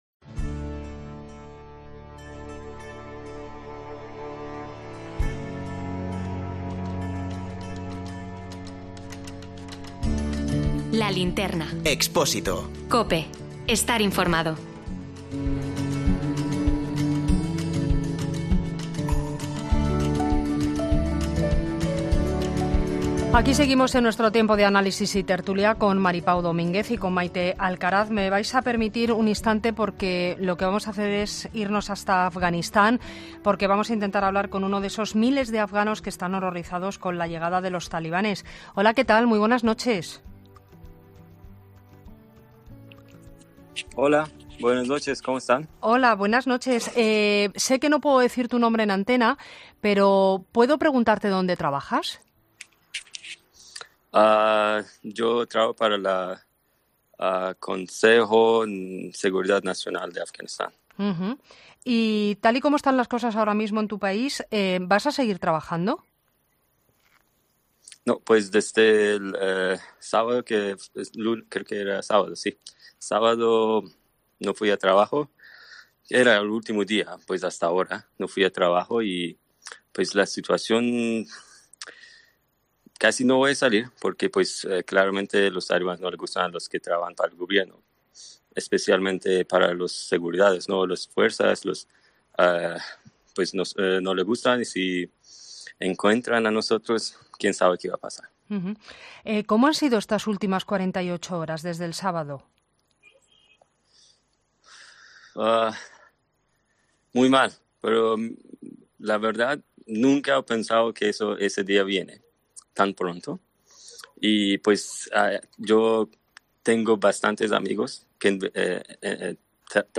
Escuchamos el testimonio de un trabajador del gobierno afgano que nos cuenta cómo ha cambiado la vida en Kabul en estos días